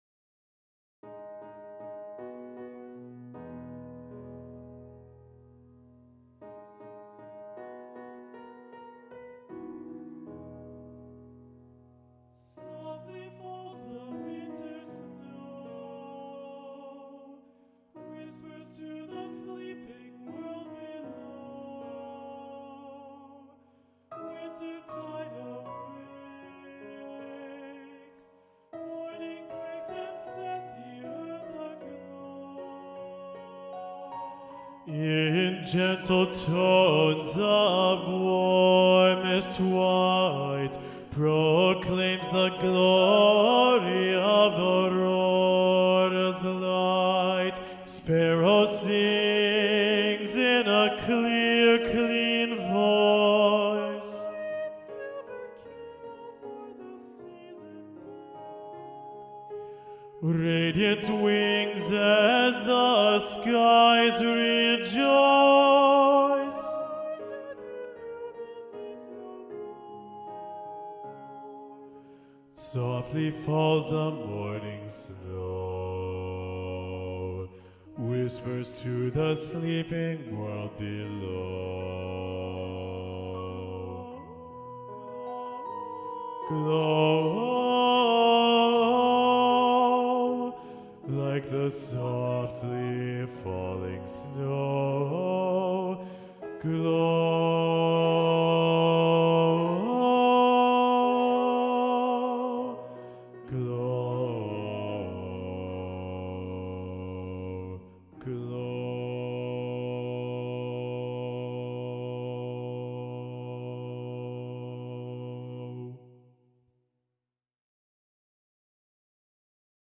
- Chant normalement à 4 voix mixtes SATB + piano
SATB Bass Predominant